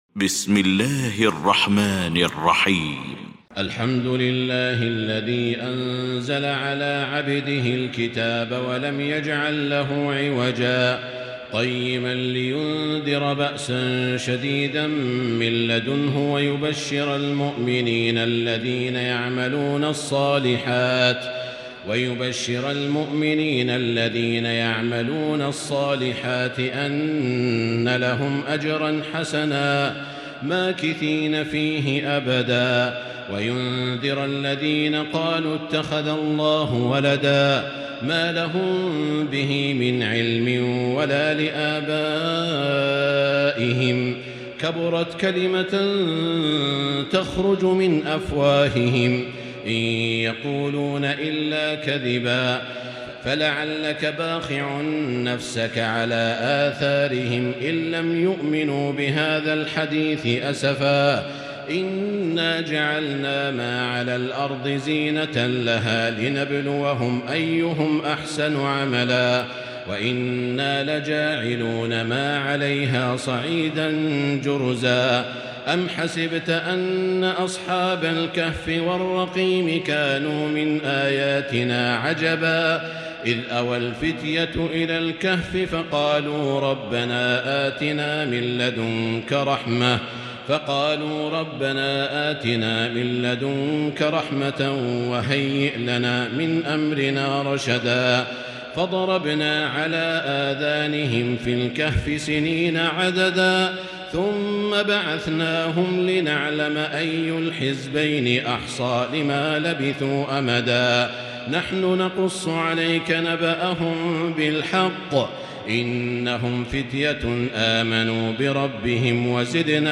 المكان: المسجد الحرام الشيخ: معالي الشيخ أ.د. بندر بليلة معالي الشيخ أ.د. بندر بليلة سعود الشريم فضيلة الشيخ ياسر الدوسري الكهف